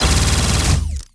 Added more sound effects.
GUNAuto_Plasmid Machinegun C Burst_01_SFRMS_SCIWPNS.wav